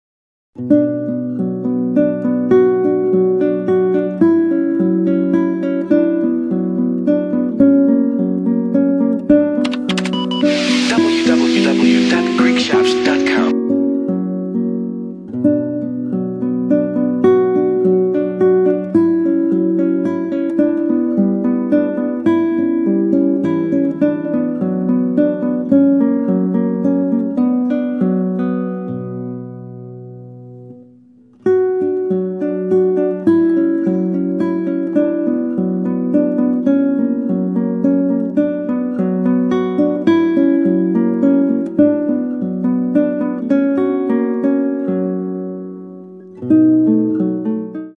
Instrumental Lullabies included on the CD: